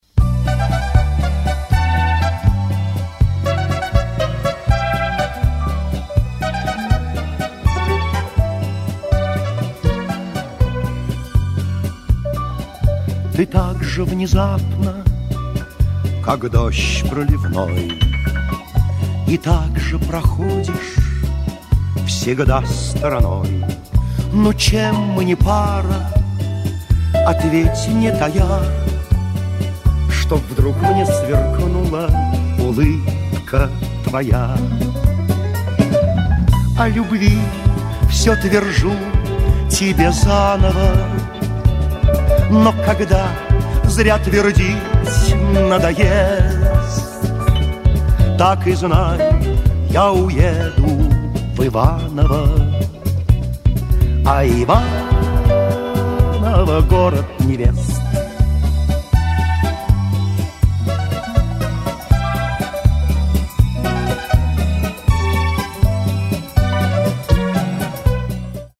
Песня